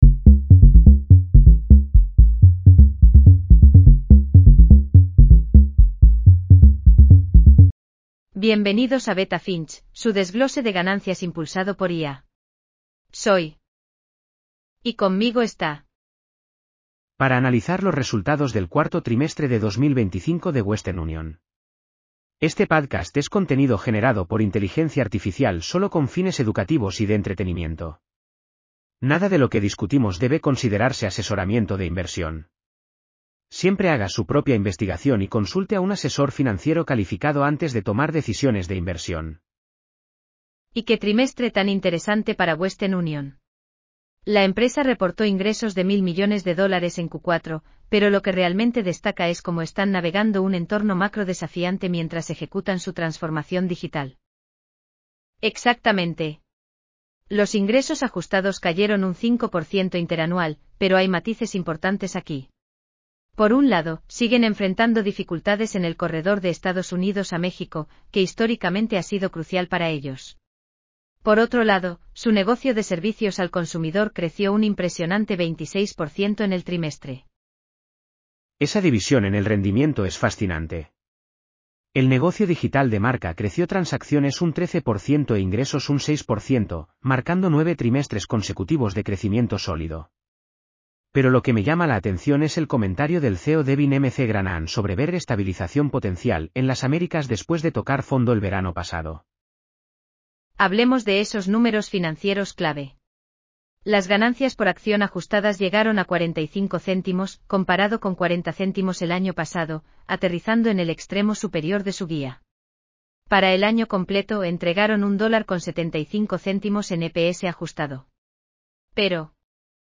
• AI-generated insights and analysis